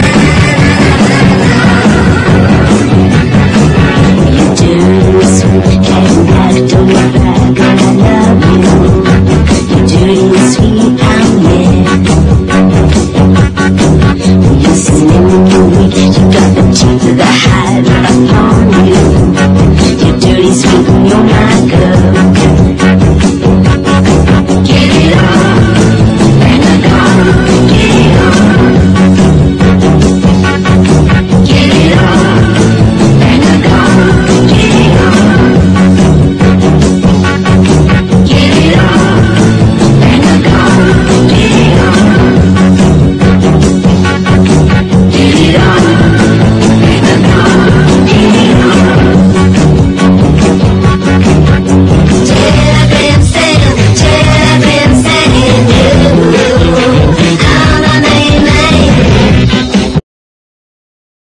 NEO-ACO/GUITAR POP / NEW WAVE / JAZZY NEW WAVE / POPCORN
ゴリゴリのベース音＋スピリチュアルなホーンも印象的な